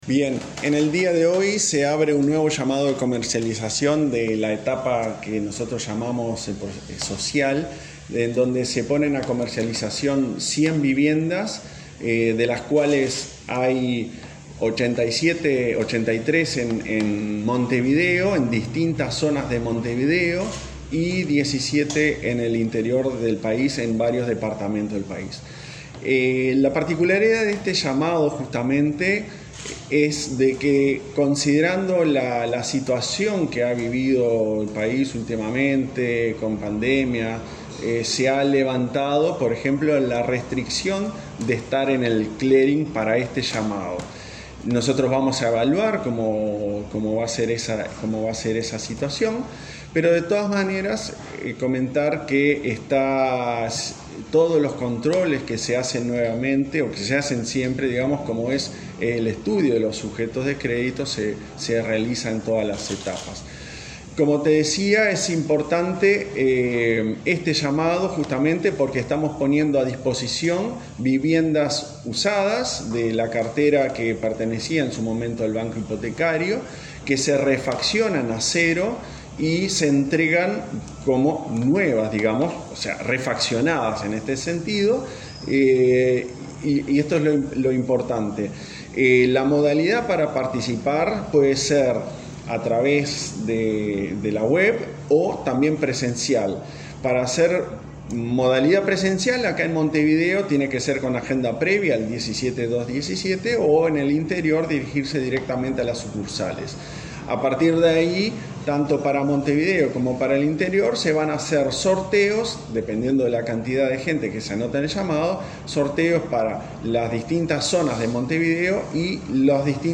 Entrevista al presidente de la ANV, Klaus MIll